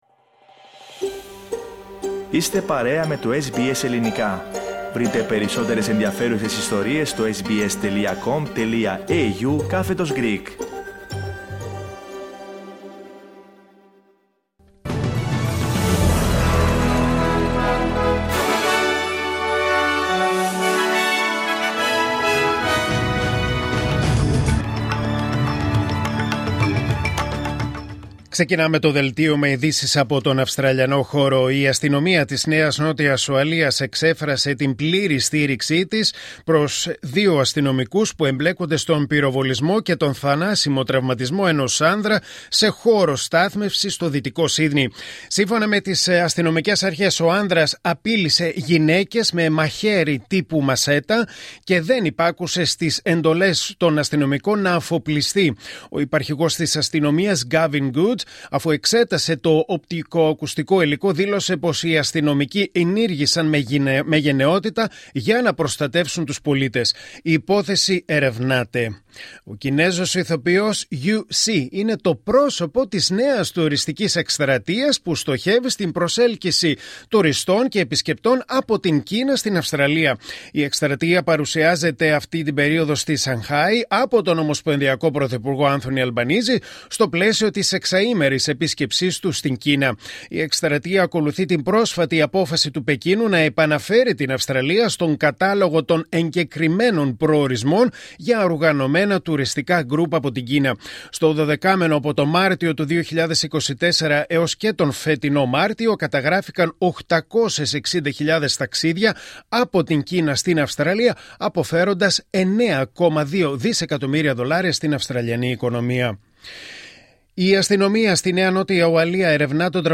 Δελτίο Ειδήσεων Κυριακή 13 Ιουλίου 2025